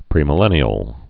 (prēmĭ-lĕnē-əl)